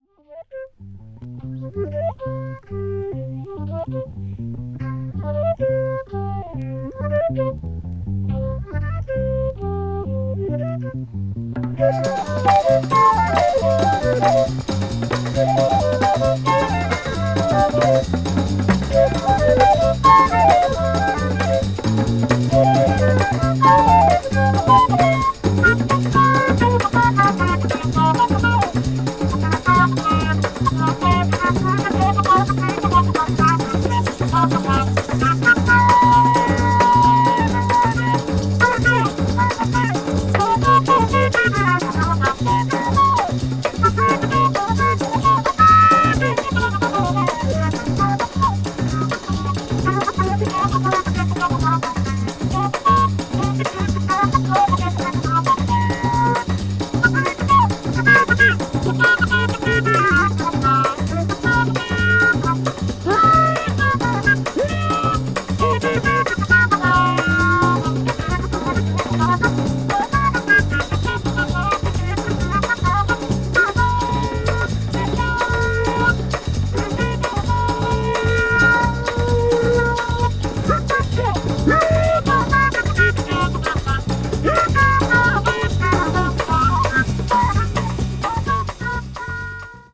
media : EX-/EX-(薄いスリキズによるわずかなチリノイズが入る箇所あり,A1中盤:軽いプチノイズ2回あり)